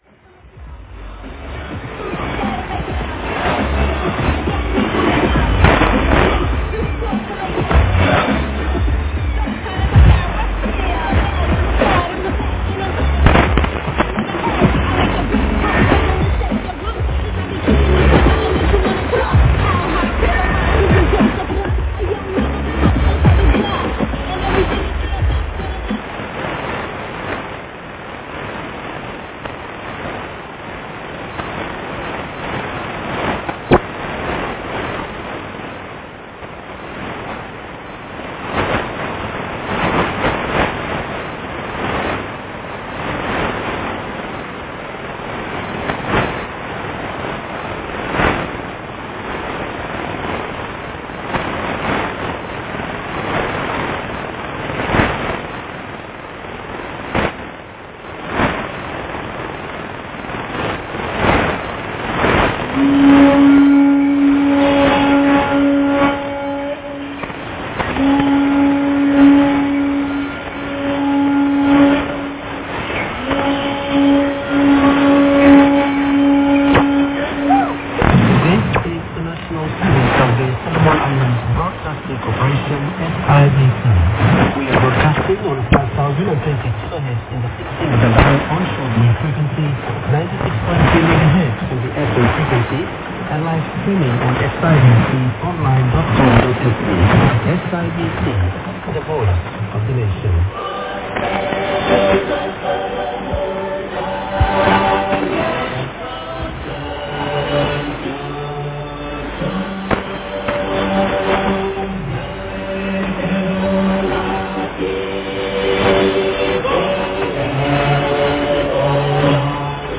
今回は04時前に音楽がフェードアウトして04時ちょうどに本放送が開始。
前回より弱かったですが良く聞こえておりました。
女性開始トークでは「16th April, 2025」と今日の日付をアナウンスしておりました。
<受信地：東京都江東区新砂 東京湾荒川河口 RX:ICF-SW7600GR ANT:AN-12>
※00:00-00:26 ♪Jump (Extended Mix) by Kris Kross.
※00:26-01:02 無音
※01:02-01:40 SIBC本放送開始アナウンス
※01:40-02:59 ♪ソロモン諸島国歌
※02:59-04:01 女性放送開始トーク